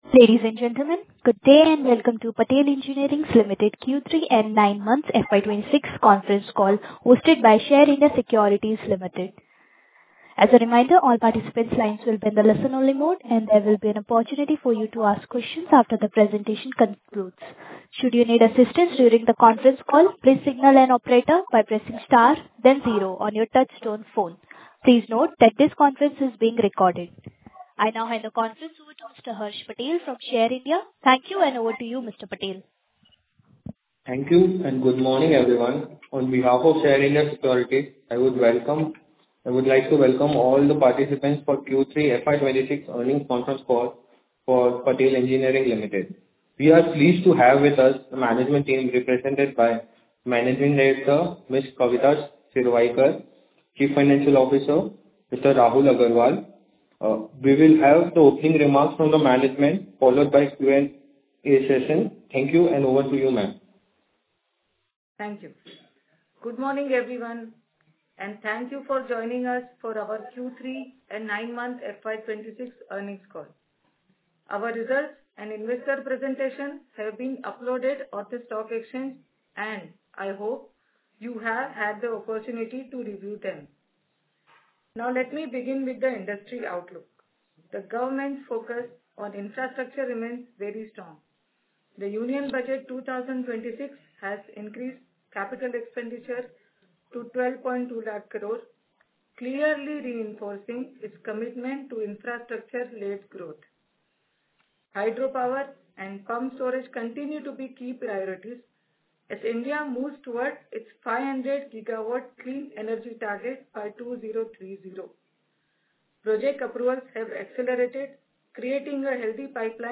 Investor/Analyst Meet Audio Recording : February 16, 2026
Patel Engineering_Q3 & 9M FY26 Confcall.mp3